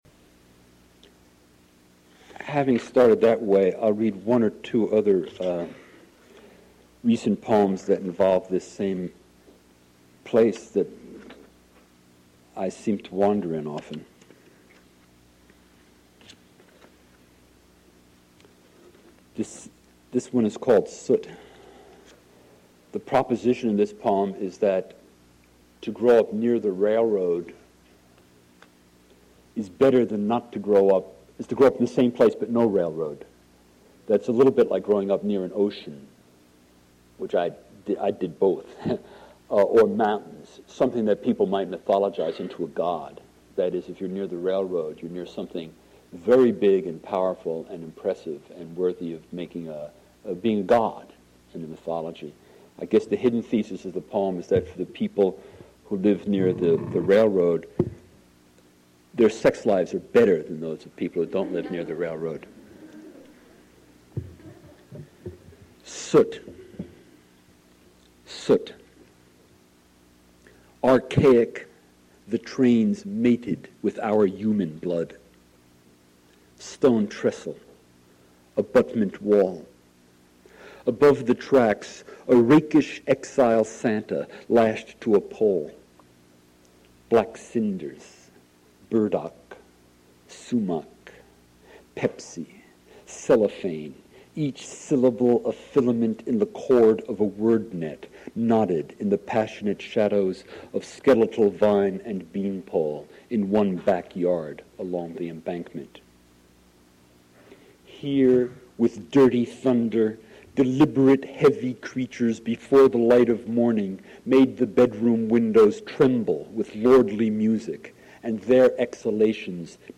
Spring Reading Series
Modern Languages Auditorium